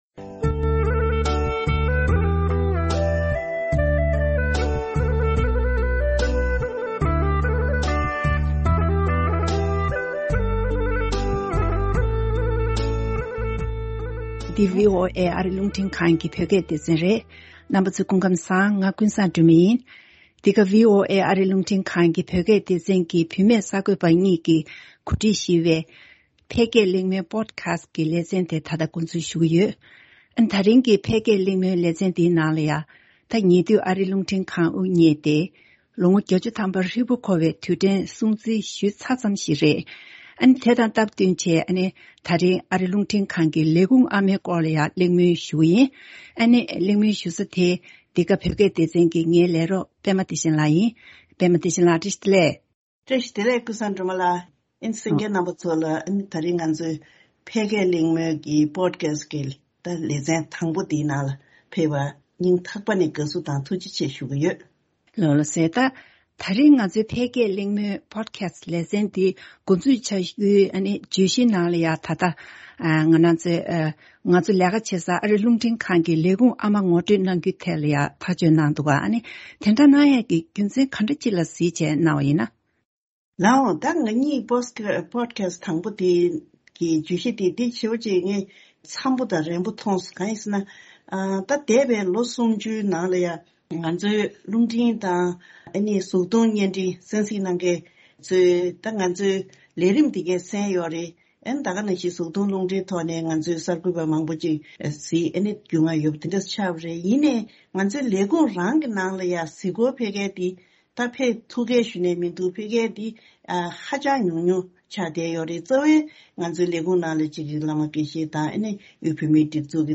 ཕལ་སྐད་གླེང་མོལ།
བུད་མེད་གསར་འགོད་པ